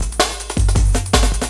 06 LOOP08 -L.wav